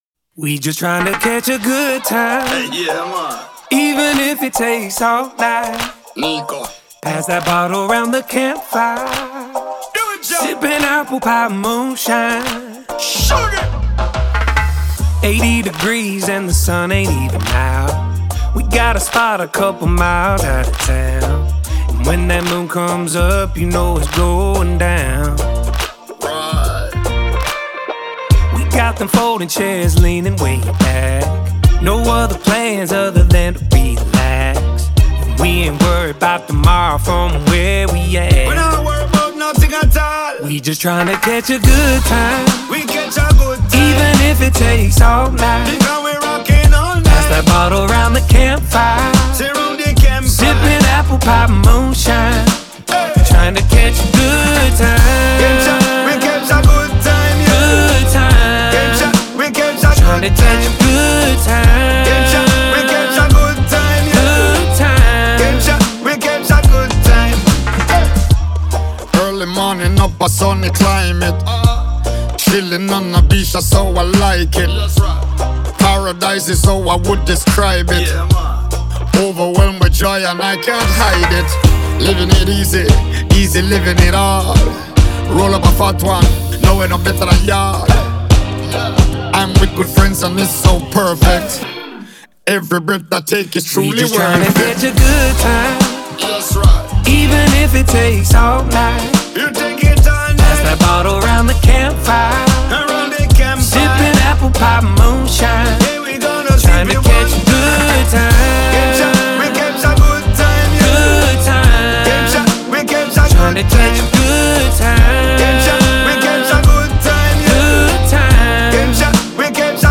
это зажигательная композиция в жанре кантри-поп